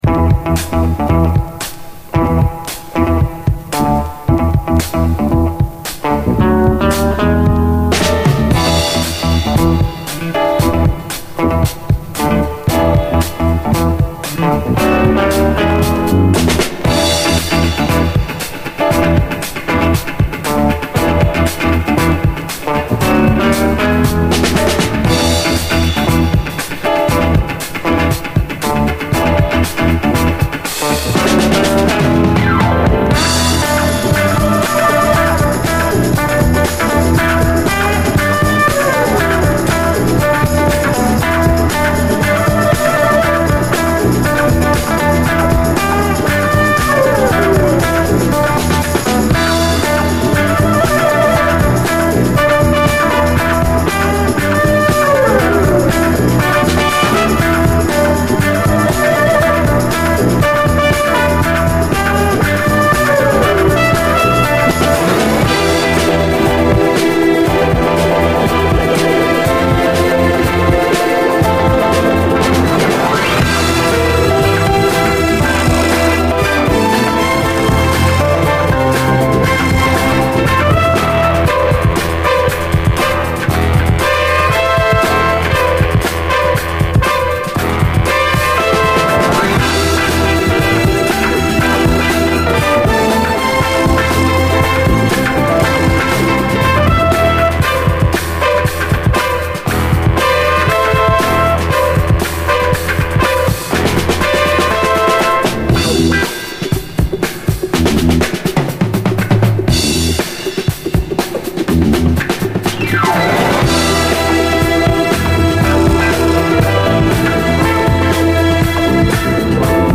SOUL, 70's～ SOUL, 7INCH
強烈に主張するストリングス〜ピアノ・フレーズがゾクゾクするほどスリリングです！
ブラック・サントラ風のファンク
中盤以降のストリングス〜ピアノ・フレーズがゾクゾクするほどスリリングでカッコいい！